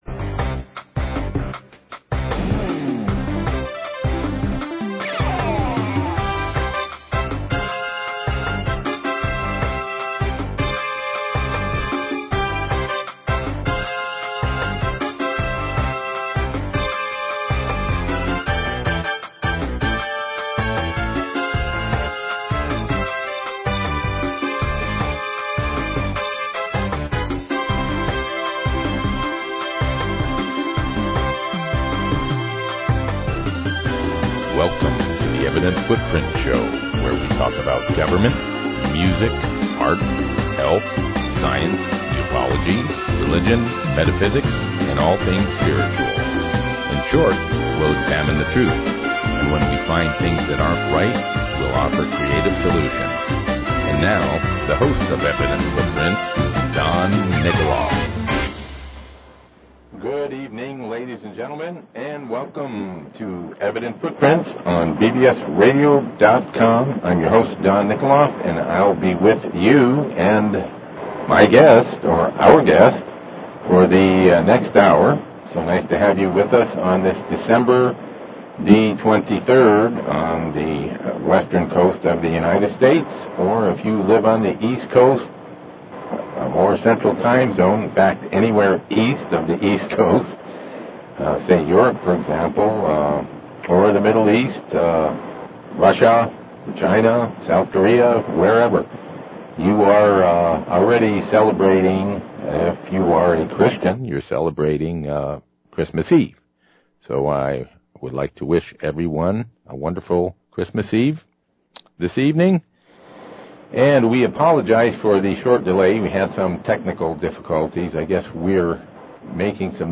Talk Show Episode, Audio Podcast, Evident_Footprints and Courtesy of BBS Radio on , show guests , about , categorized as
Civil Rights/Antitrust Attorney